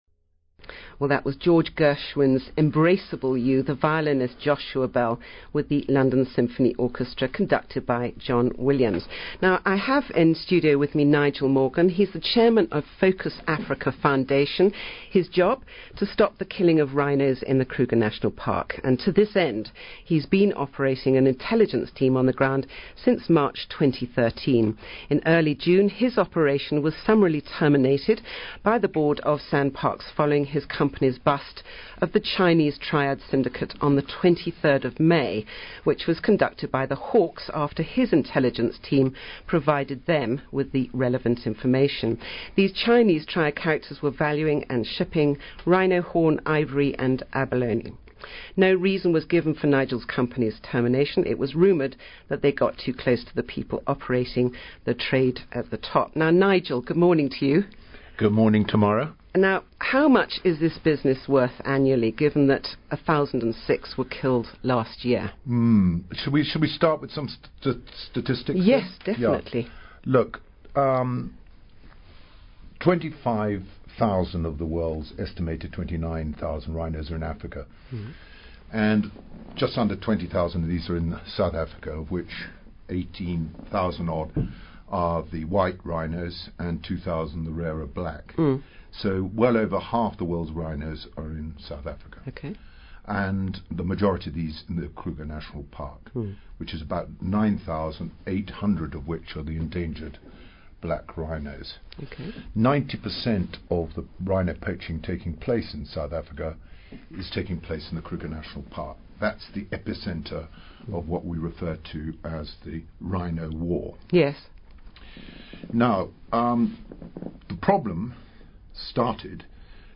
This interview on Classic FM